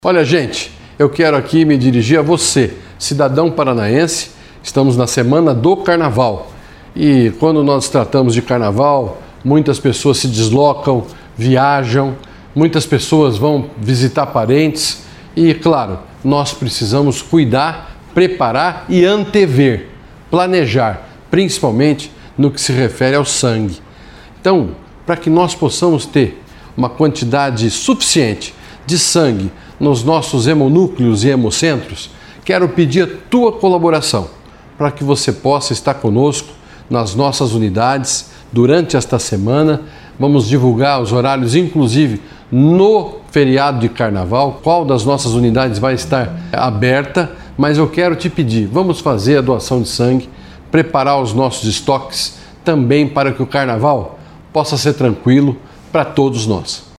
O secretário da Saúde do Paraná, Beto Preto, reforça o pedido aos doadores de sangue.
BETO-PRETO-DOACAO-DE-SANGUE.mp3